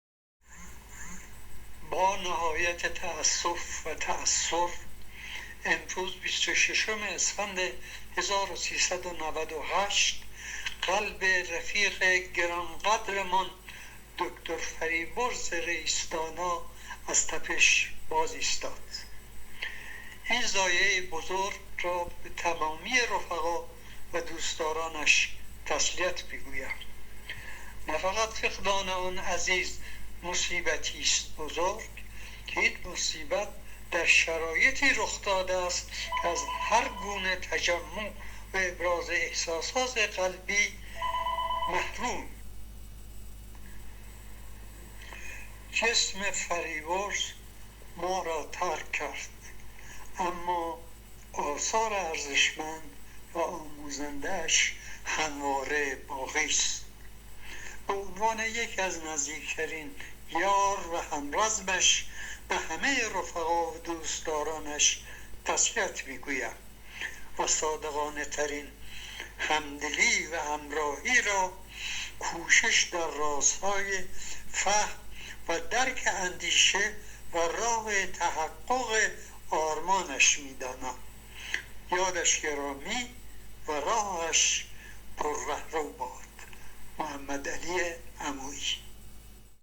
پیام صوتی